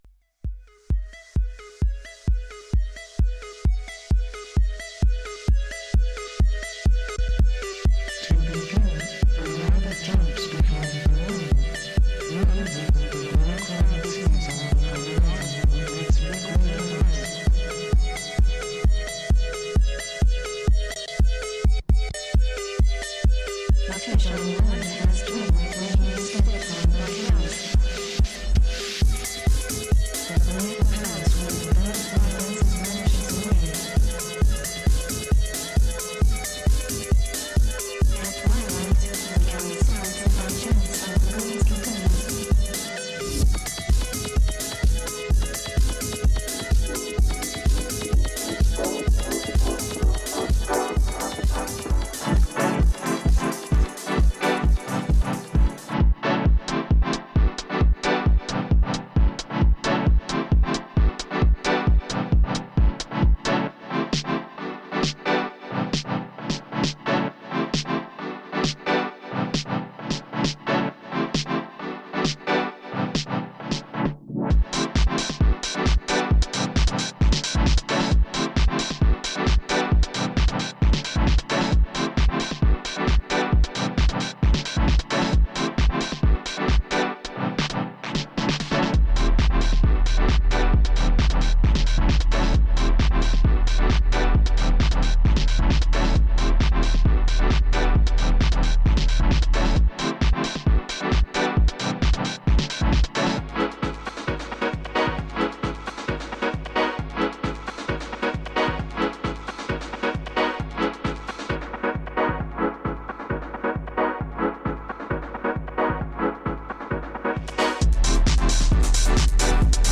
I spent a couple day trying out different software and ended up with a mix I was ok with sharing.
I'll create a archive of mixes as (and if) I make more, but for now, here's my premier set!